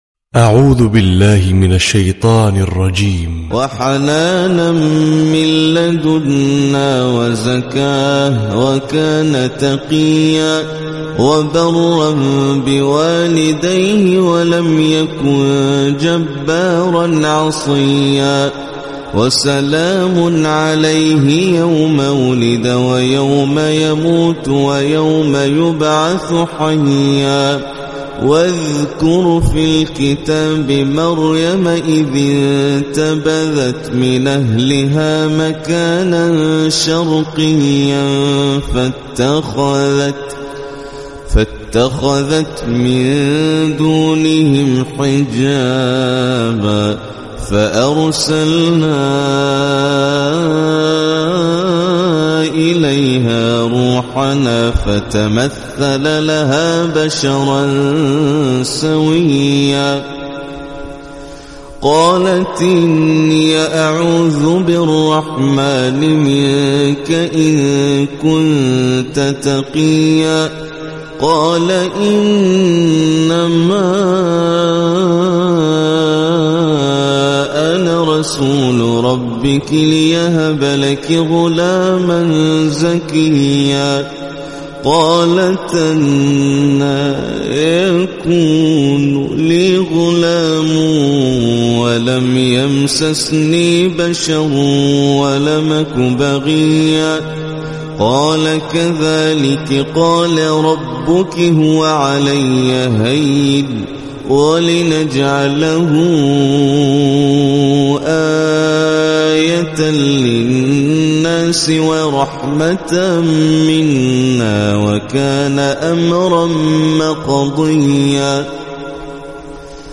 🕋🌻•تلاوة صباحية•🌻🕋
★برواية ورش عن نافع★